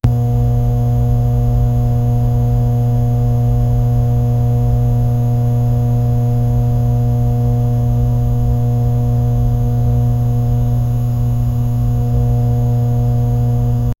The mechanical hum of solar panels adjusting position 0:14 Created Mar 17, 2025 12:58 PM Tim adjusted his leather jacket as he stepped out of his cramped, book-filled apartment. 0:06 Created Apr 1, 2025 10:38 AM
the-mechanical-hum-of-sol-4thluihx.wav